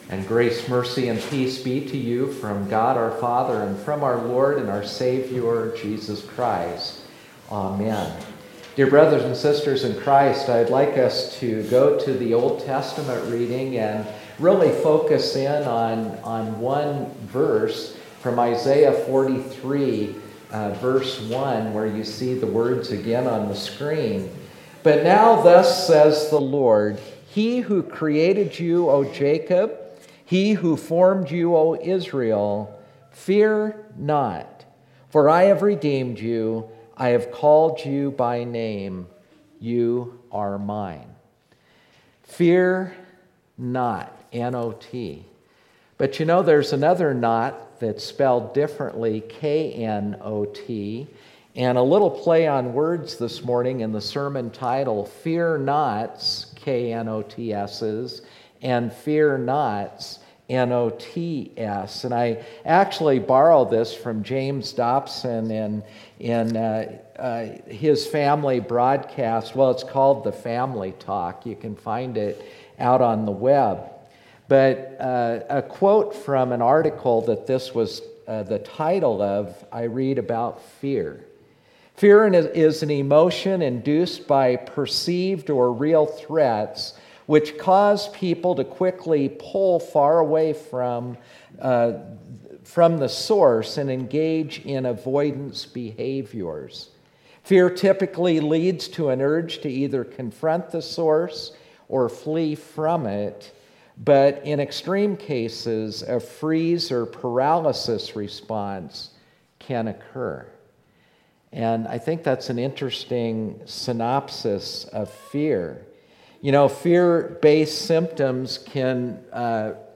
Trinity Lutheran Church, Greeley, Colorado Fear Knots and Fear Nots Jan 13 2019 | 00:22:09 1x 00:00 / 00:22:09 Subscribe Share RSS Feed Share Link Embed